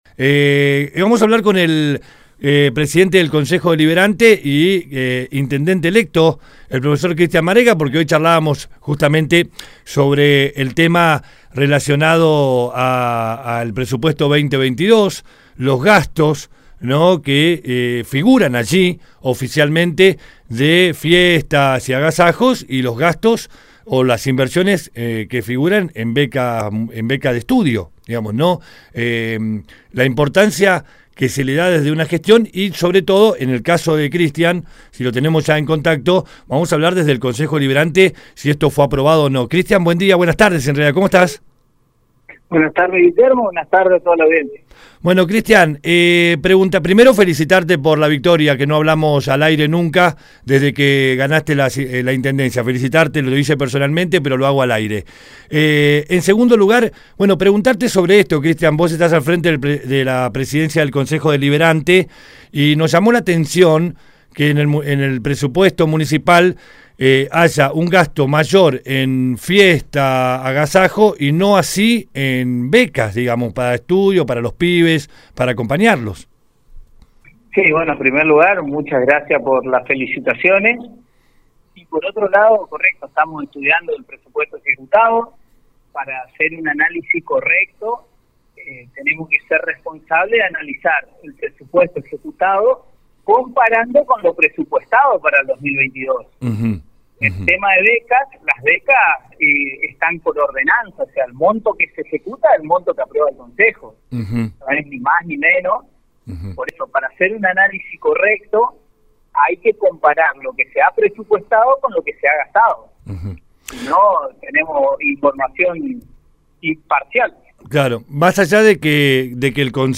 GASTOS EN FIESTAS Y AGASAJOS INVERSION EN BECAS DE ESTUDIO A raíz de este tema, dialogamos con el presidente del Consejo deliberante e intendente electo Cristian Marega , quién dijo que todavía ese presupuesto ejecutado 2022 no ha sido aprobado por el consejo deliberante, a pesar de que estamos entrando en el mes de octubre todavía está en discusión, cuando lo consultamos sobre si él estaba de acuerdo que se gaste más dinero en fiestas y agasajos que en becas dijo que no, pero que el monto destinado para becas está regulado por ordenanza y que todavía siguen discutiendo la aprobación del ejecutado 2022.